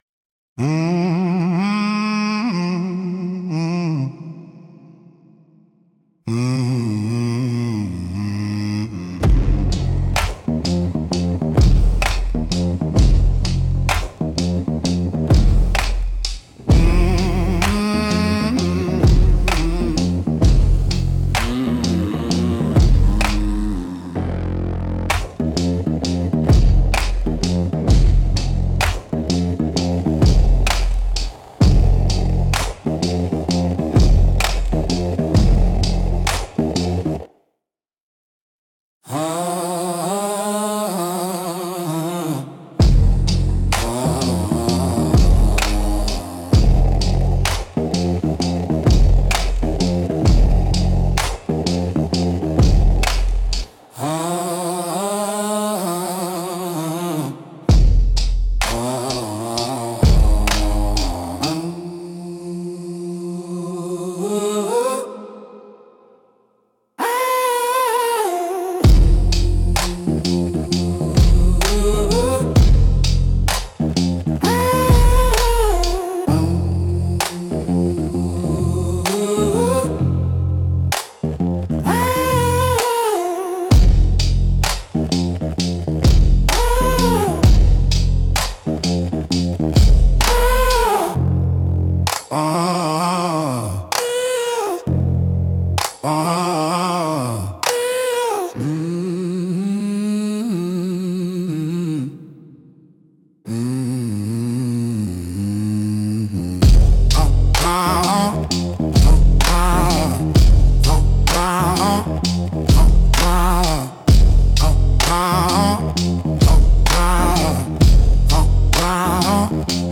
Instrumental - Ghostwire Gospel 2.17